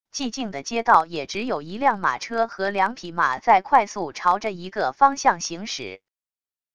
寂静的街道也只有一辆马车和两匹马在快速朝着一个方向行驶wav音频